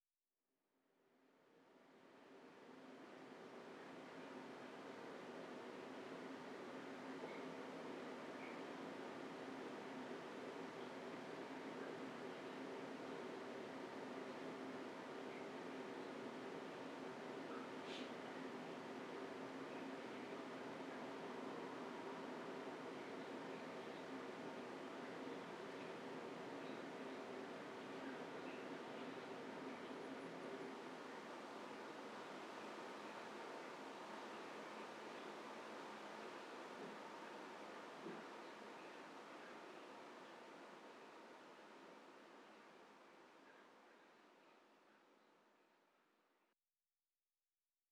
04_书店内.wav